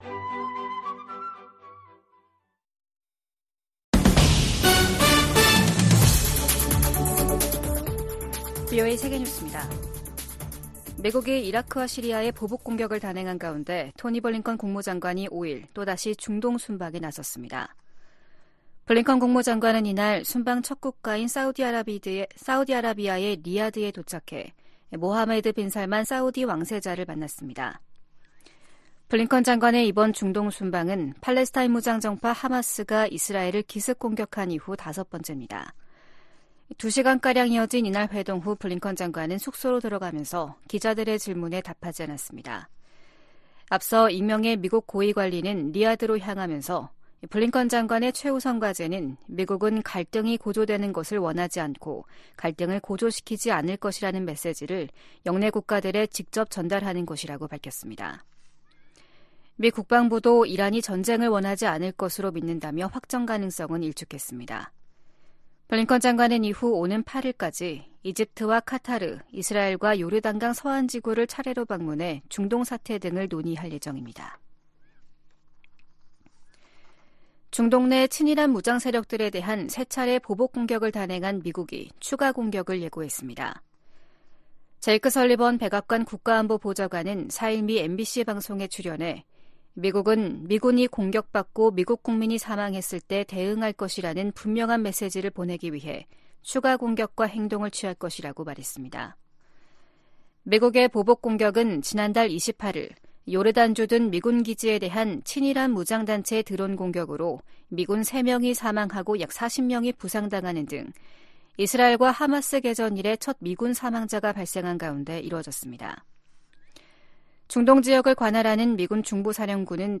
VOA 한국어 아침 뉴스 프로그램 '워싱턴 뉴스 광장' 2024년 2월 6일 방송입니다. 북한은 순항미사일 초대형 전투부 위력 시험과 신형 지대공 미사일 시험발사를 지난 2일 진행했다고 대외 관영 ‘조선중앙통신’이 다음날 보도했습니다. 미국 정부는 잇따라 순항미사일을 발사하고 있는 북한에 도발을 자제하고 외교로 복귀하라고 촉구했습니다. 미국 정부가 미국내 한인 이산가족과 북한 가족들의 정보를 담은 기록부를 구축하도록 하는 법안이 하원에서 발의됐습니다.